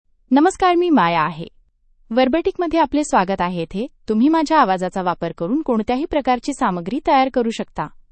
Maya — Female Marathi AI voice
Maya is a female AI voice for Marathi (India).
Voice sample
Listen to Maya's female Marathi voice.
Female